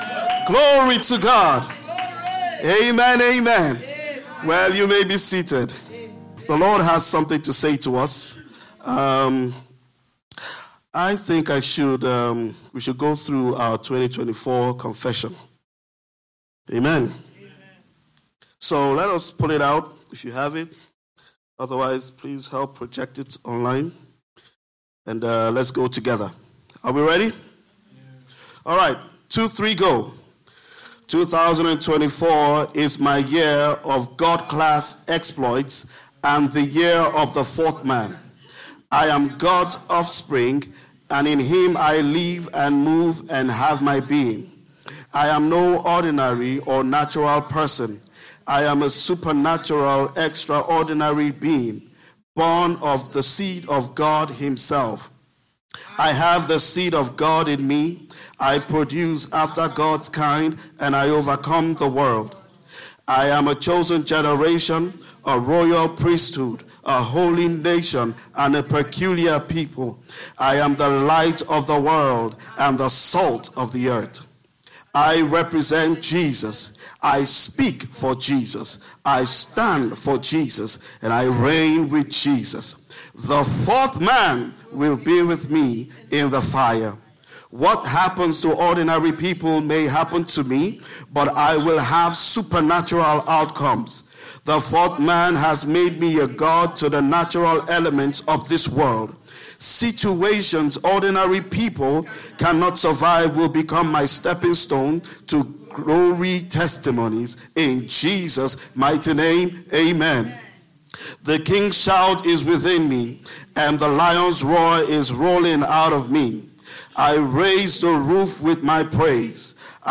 Sermons – Abundant Life International Church